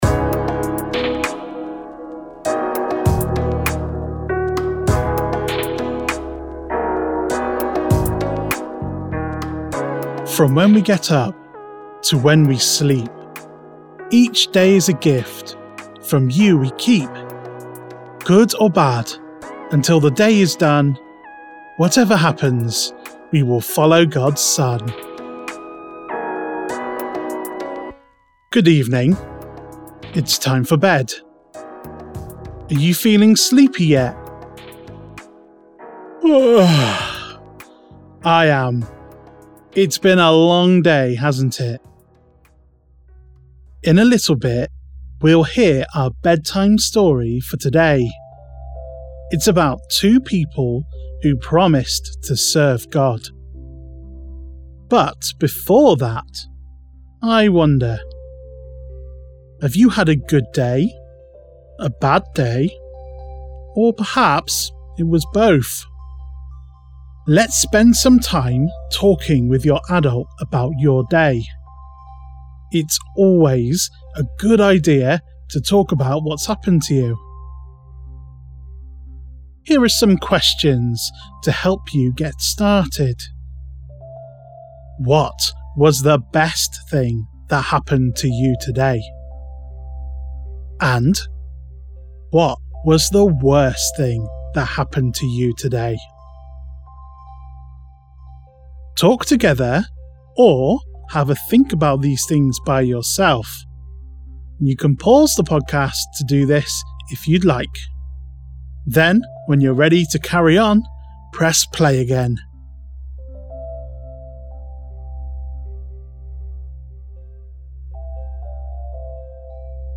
Morning and Bedtime Bible Stories for Children